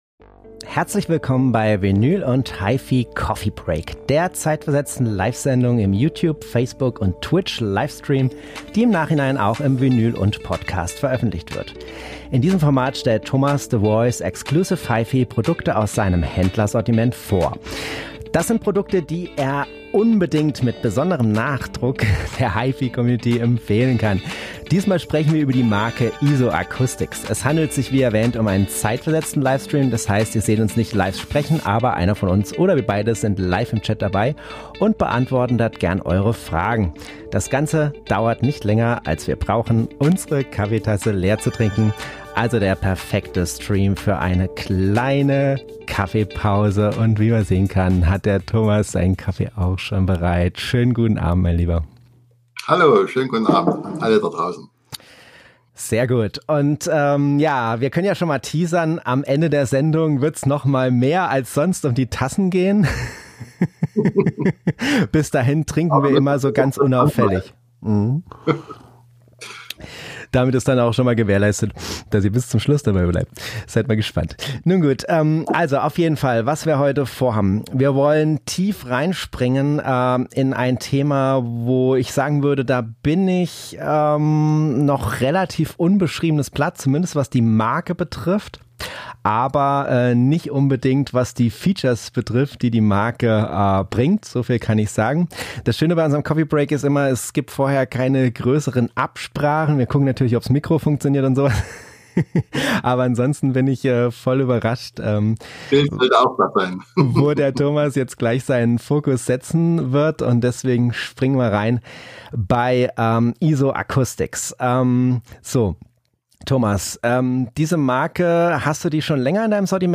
*** Letzter (zeitversetzter) Livestream *** IsoAcoustics (Vinyl & ...